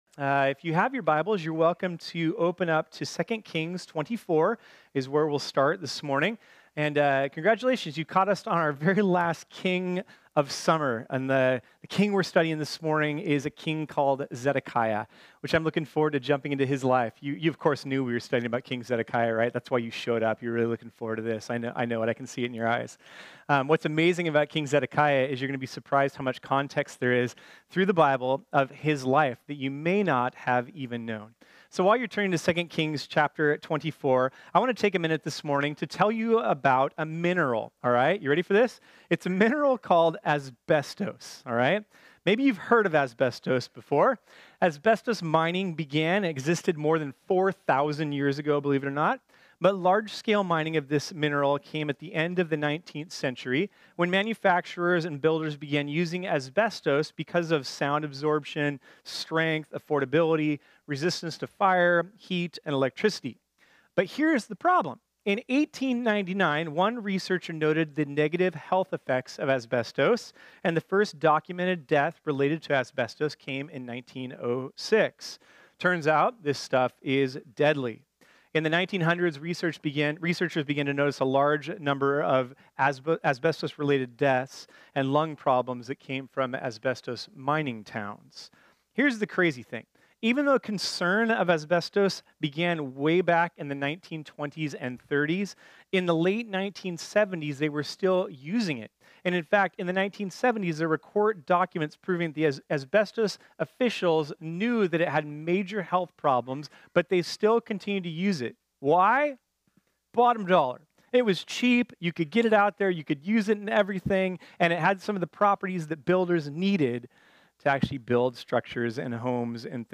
This sermon was originally preached on Sunday, August 12, 2018.